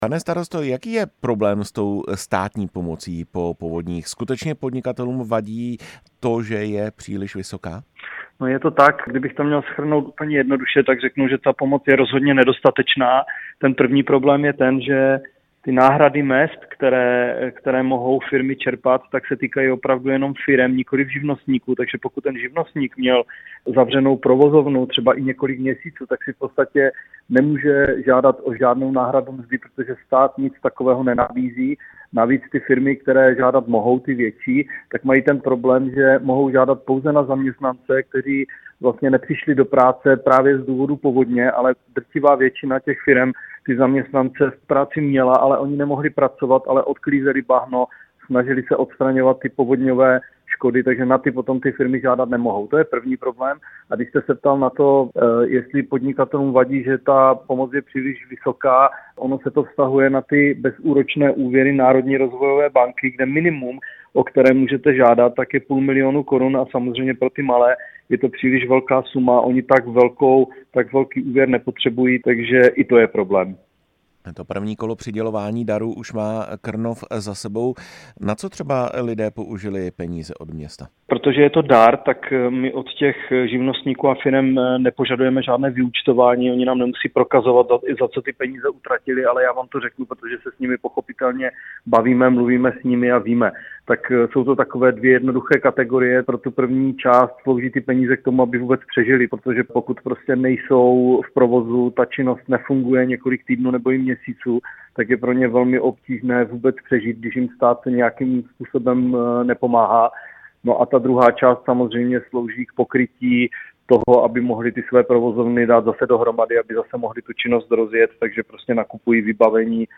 Jaký je problém se státní pomocí, jaké jsou podmínky pro získání pomoci z radnice v Krnově a na co lidé používají peníze od města? Ve vysílání Radia Prostor jsme přivítali starostu Krnova Tomáše Hradila.